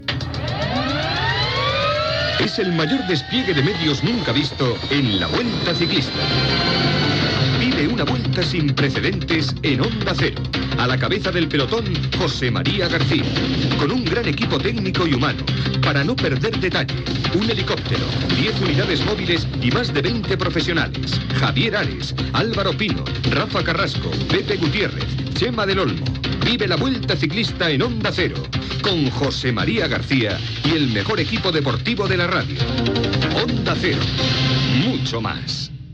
Promoció del la Vuelta ciclista a España en Onda Cero, amb els noms de l'equip